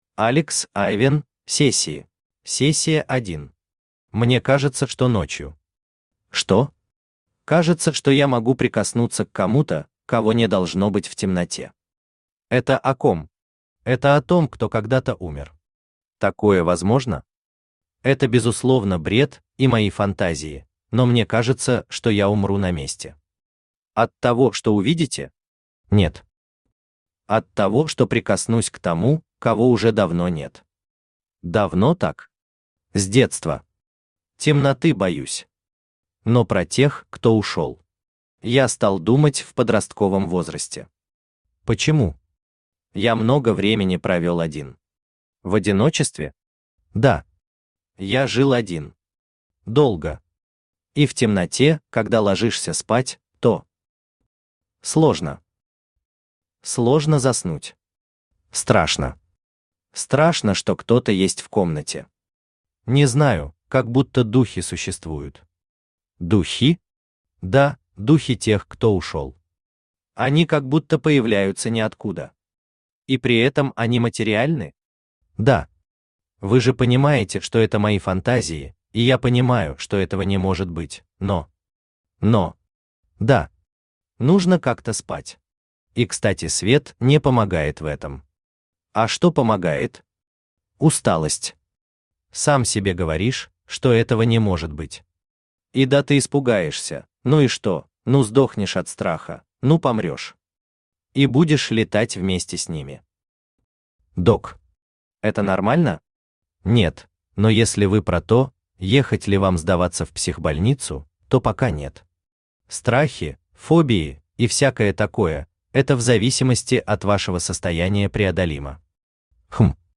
Аудиокнига Сессии | Библиотека аудиокниг
Aудиокнига Сессии Автор Алексей Иванов Читает аудиокнигу Авточтец ЛитРес.